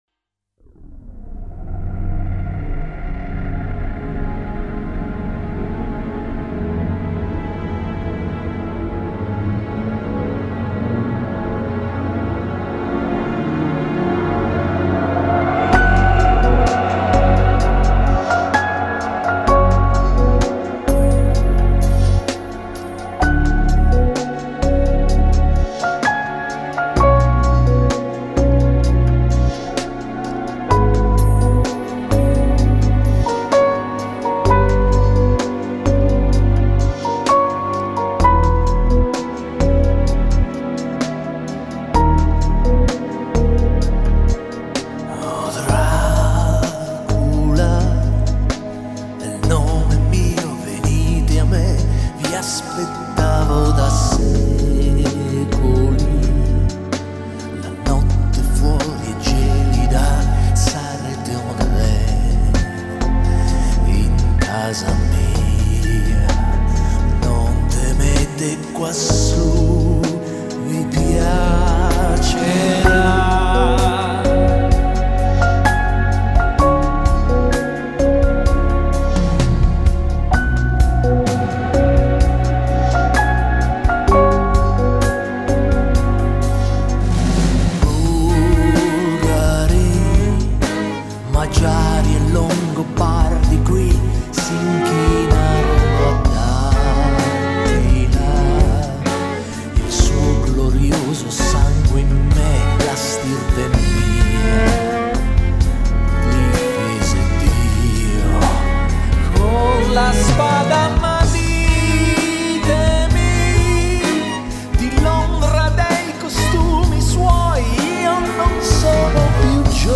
Итальянская группа прогрессивного рока